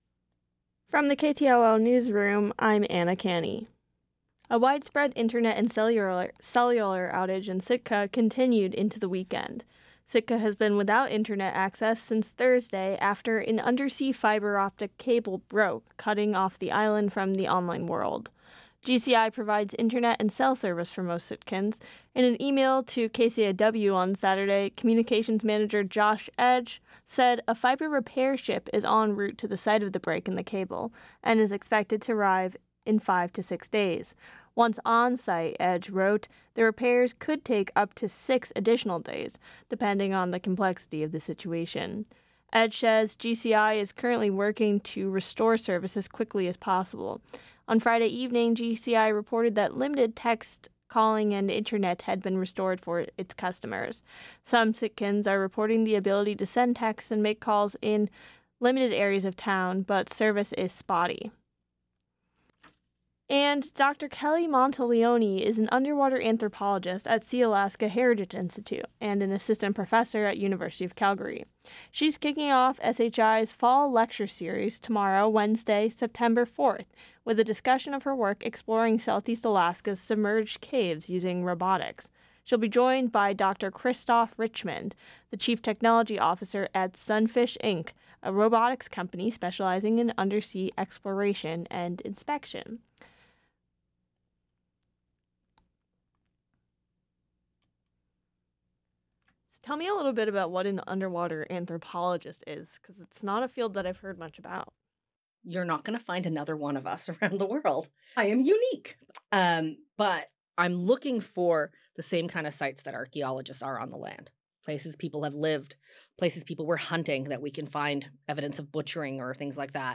Newscast – Tuesday, Sept. 3, 2024